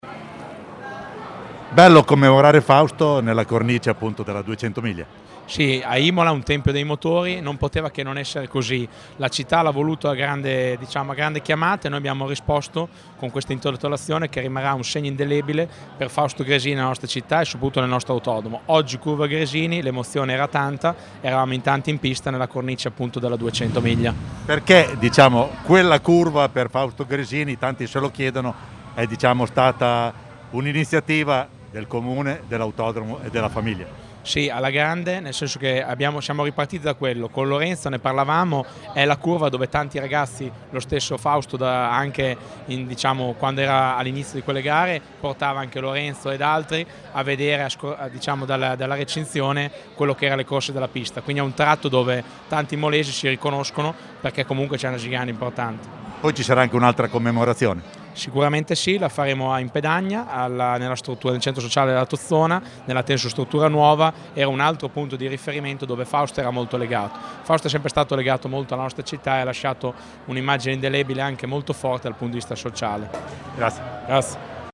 Marco-Panieri-Sindaco-di-Imola.mp3